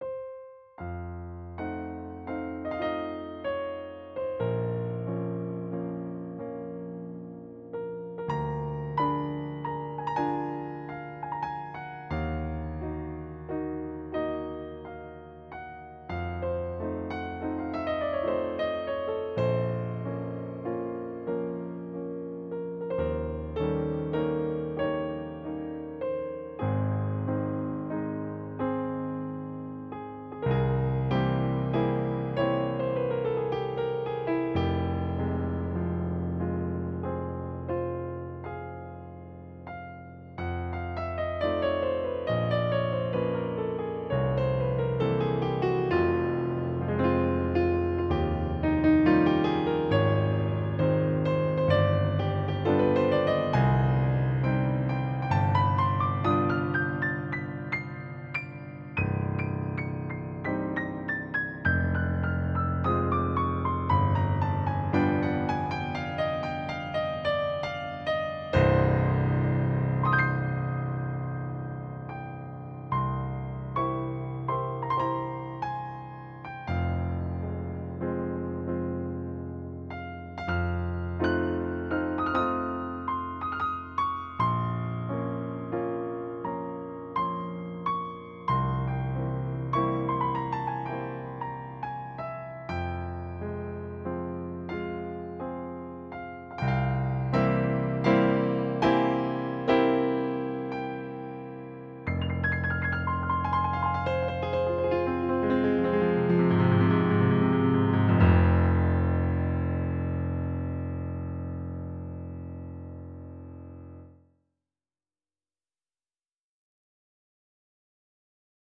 My main-hobby, composing piano music: